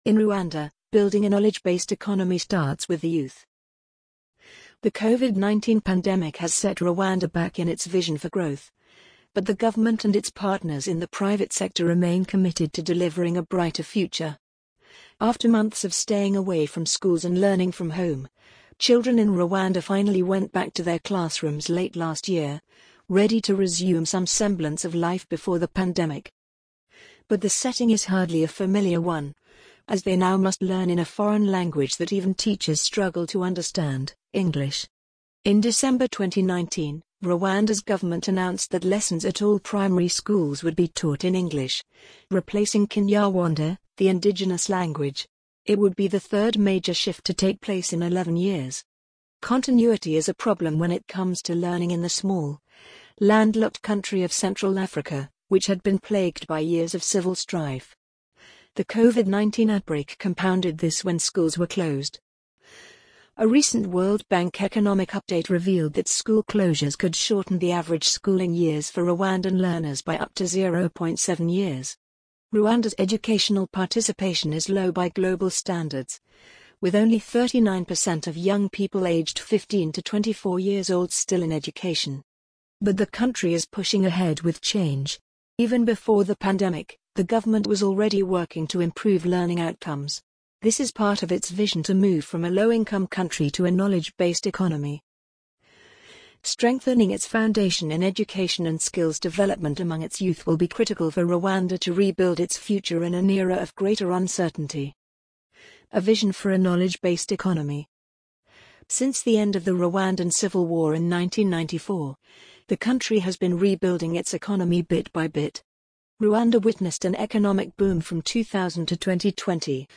amazon_polly_12789.mp3